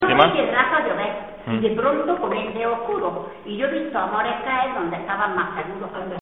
Materia / geográfico / evento: Canciones de corro Icono con lupa
Zafarraya (Granada) Icono con lupa
Secciones - Biblioteca de Voces - Cultura oral